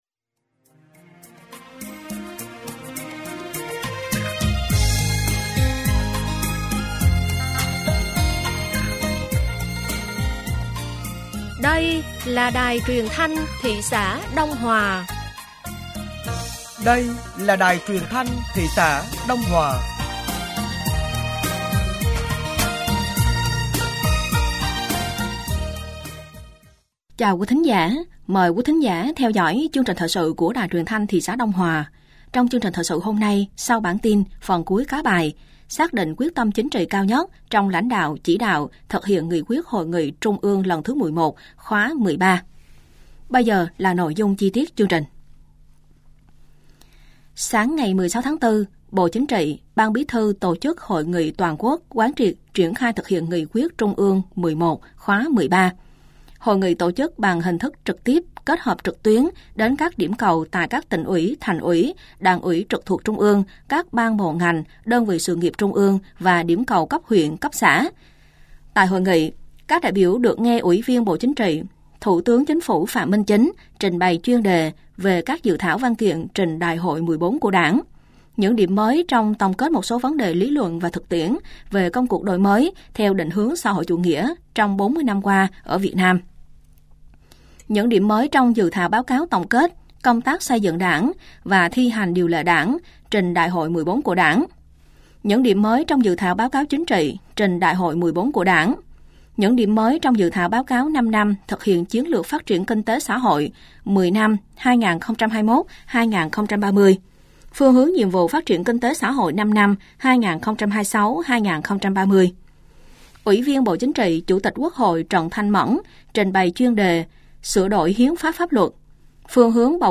Thời sự tối ngày 16 và sáng ngày 17 tháng 4 năm 2025